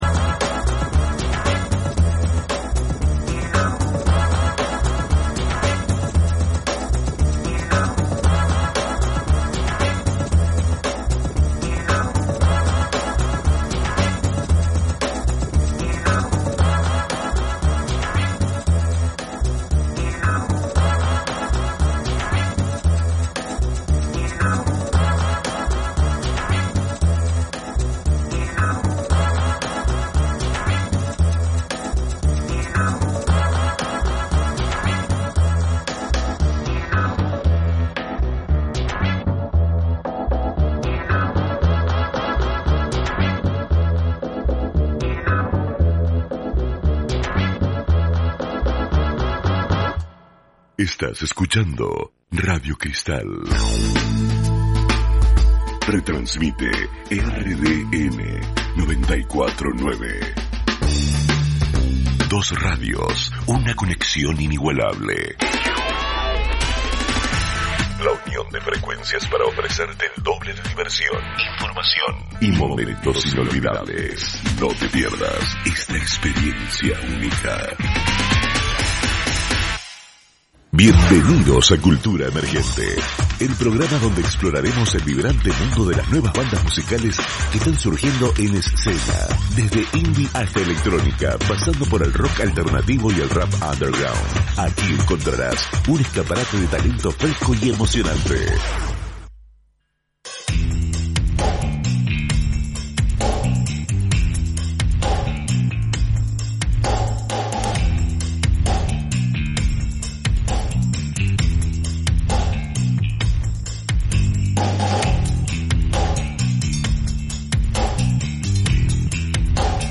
En la primera media hora escuchamos a bandas amigas en homenaje a este día.